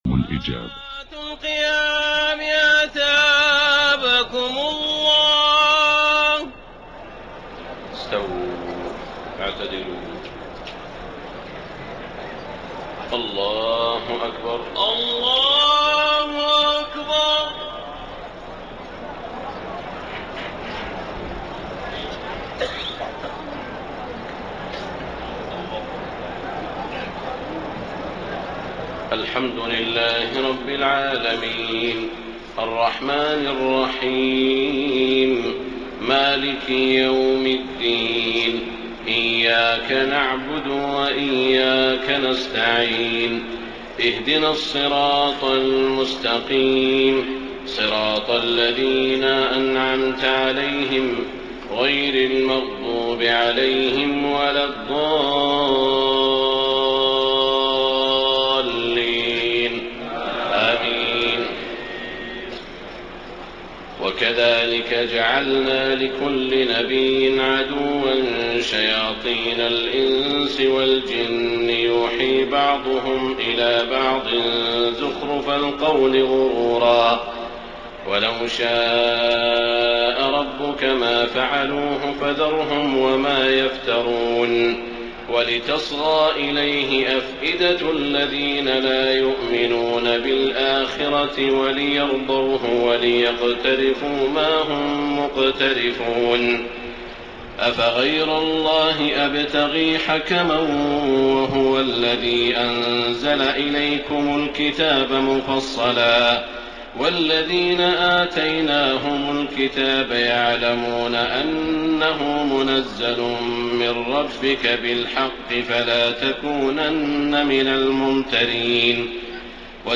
تهجد ليلة 28 رمضان 1433هـ من سورتي الأنعام (112-165) و الأعراف (1-36) Tahajjud 28 st night Ramadan 1433H from Surah Al-An’aam and Al-A’raf > تراويح الحرم المكي عام 1433 🕋 > التراويح - تلاوات الحرمين